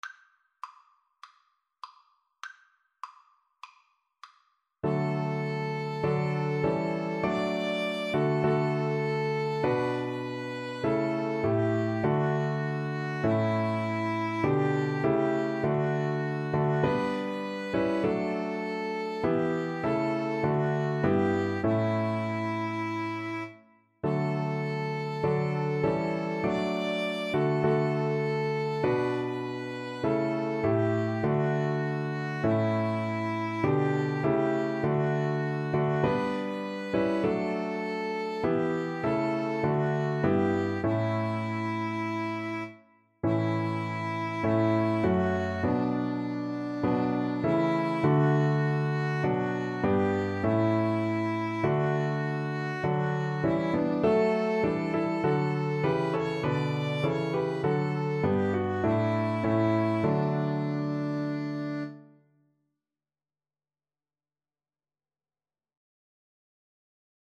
Maestoso
Piano Trio  (View more Easy Piano Trio Music)